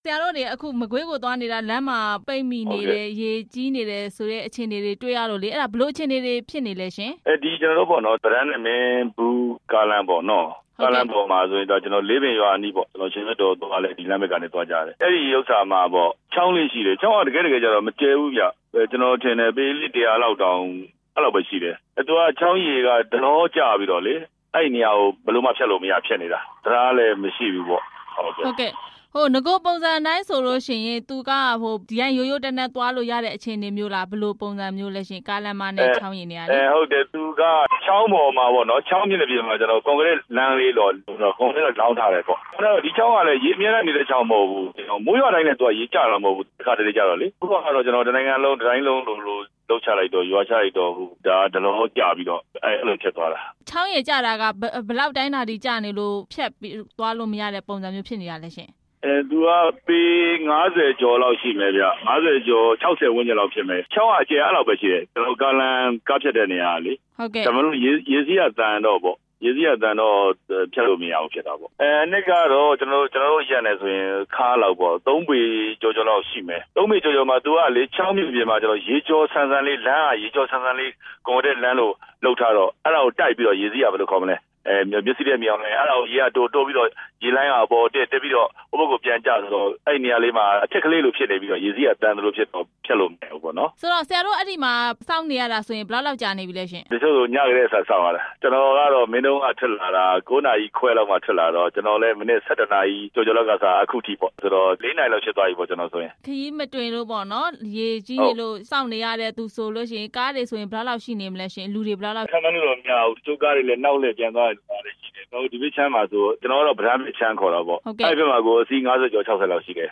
မကွေးမှာ ရေကြီးတဲ့အကြောင်း မေးမြန်းချက်